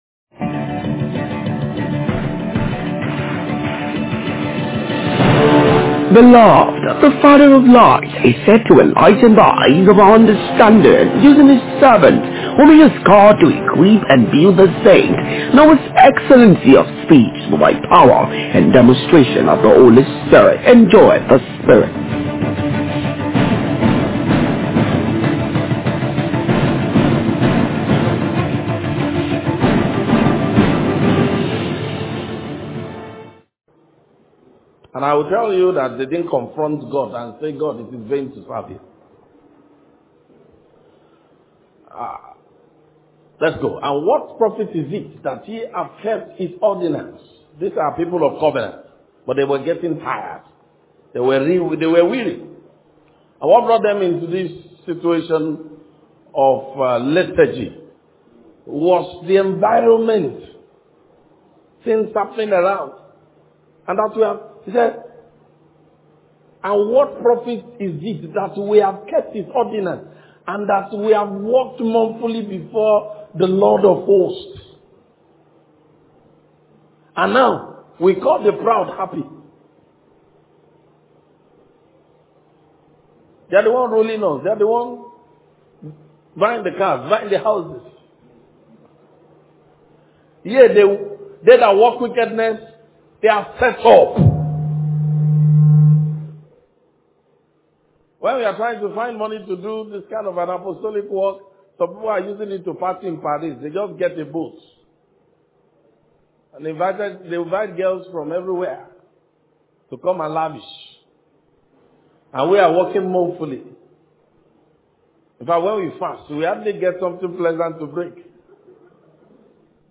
Youth Convention’19 Saviours From Zion Day 2 Morning – Power & Glory Tabernacle